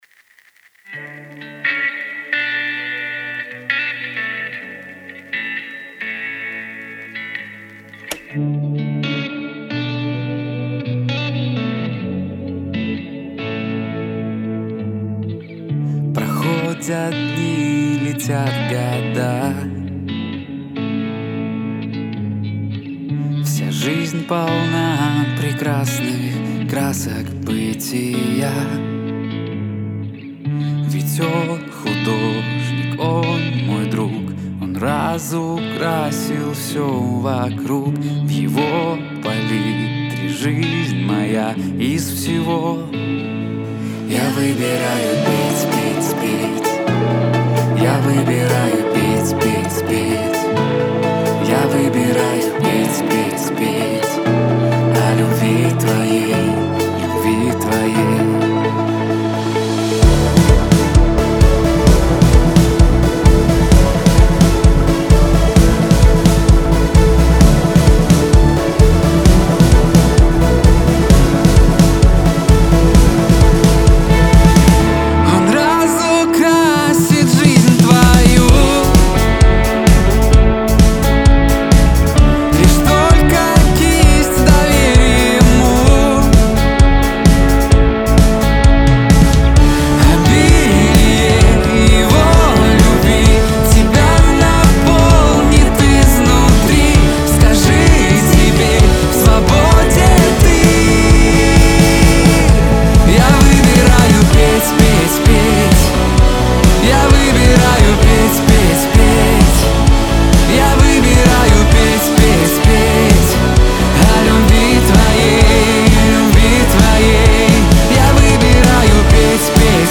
1655 просмотров 1518 прослушиваний 237 скачиваний BPM: 130